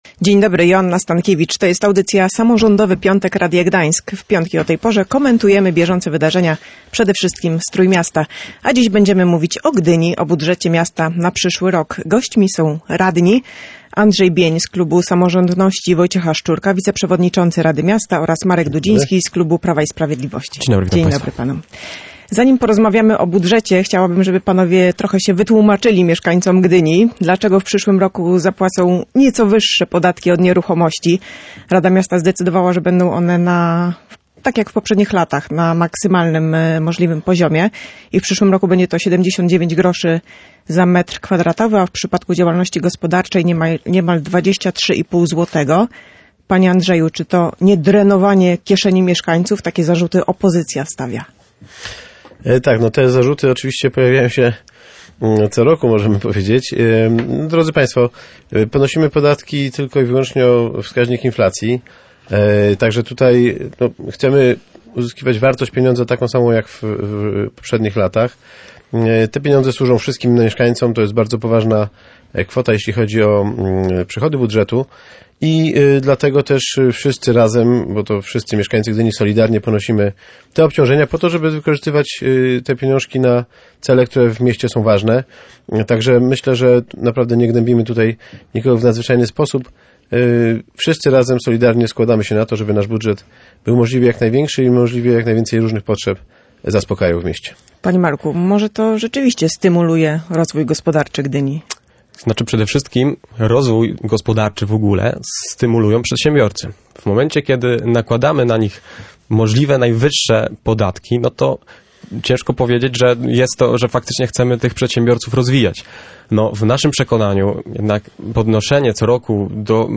w audycji Samorządowy Piątek Radia Gdańsk byli Andrzej Bień z klubu Samorządności Wojciecha Szczurka, wiceprzewodniczący Rady Miasta oraz Marek Dudziński z klubu Prawa i Sprawiedliwości.